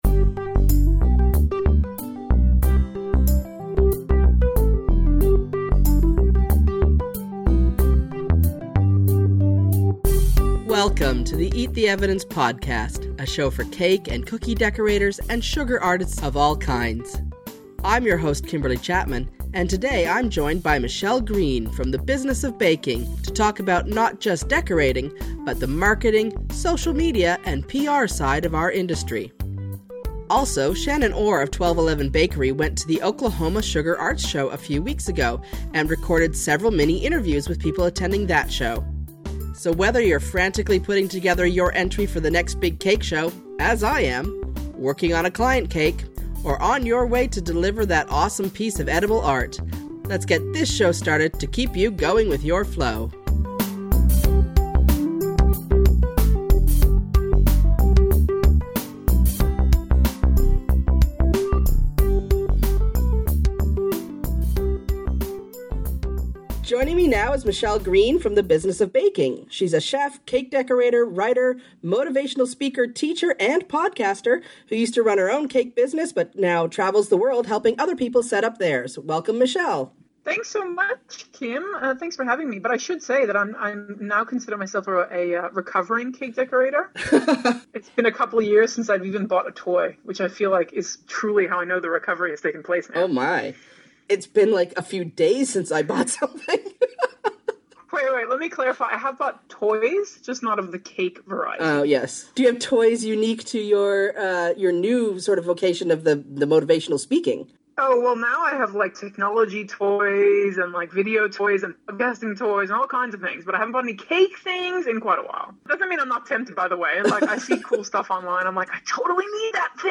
Music/Sound Credits The following songs and sound clips were used in this episode of Eat the Evidence.